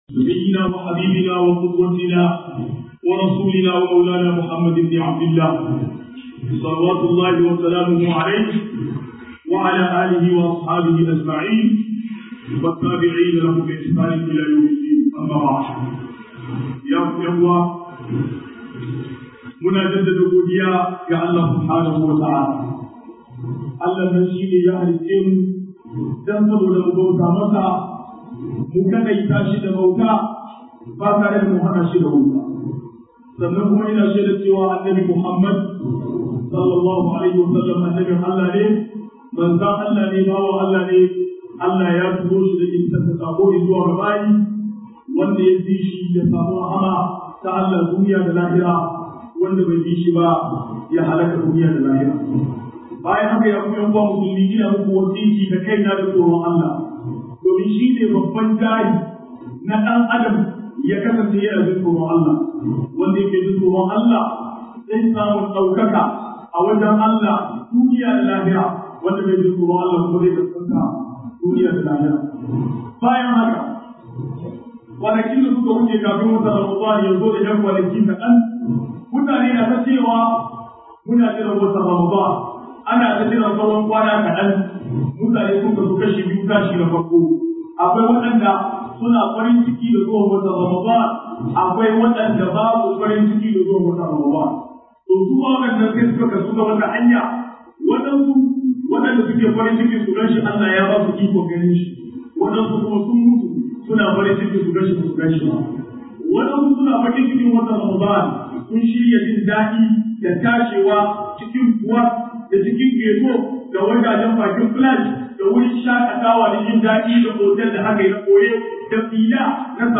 35-Hudba jouma'a 26